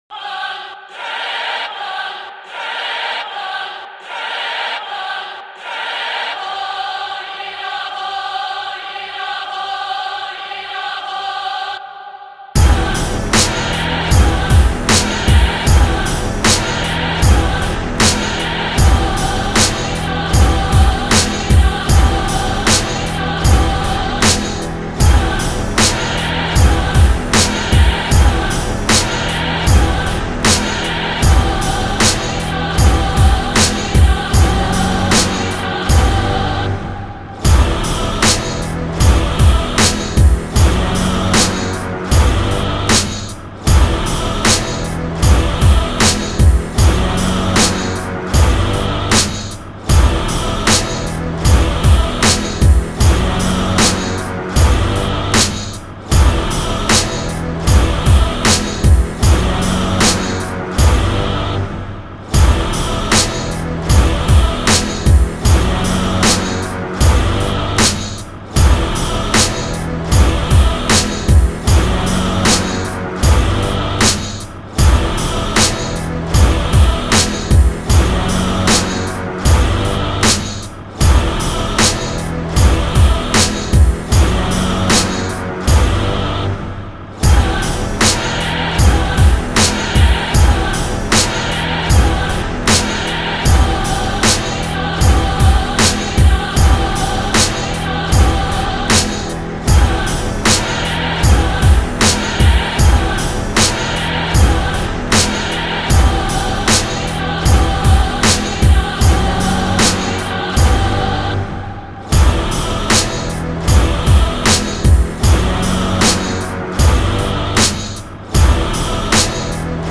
HipHop Tracks & Instrumentals
Inst.